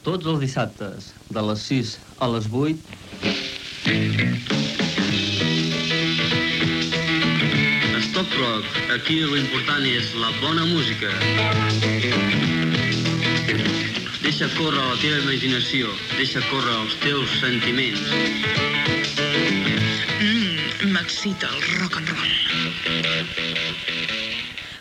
Identificació del programa Gènere radiofònic Musical